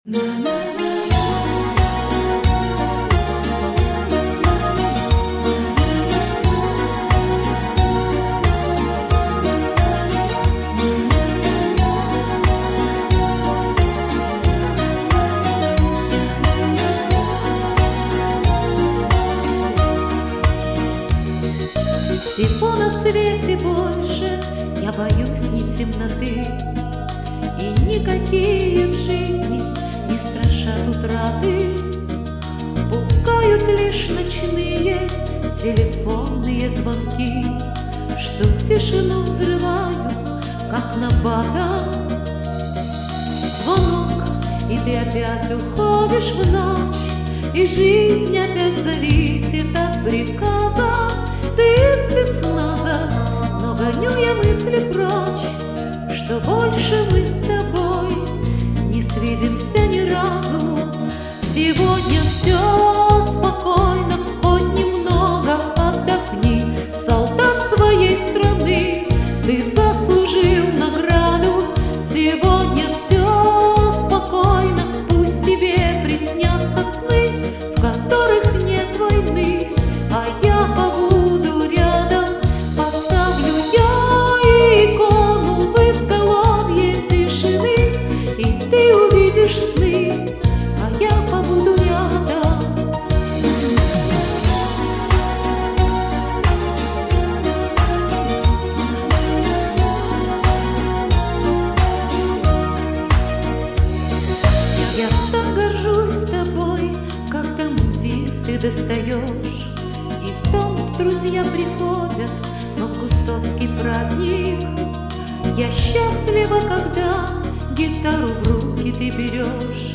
Прекрасный вокал! Отличное качество.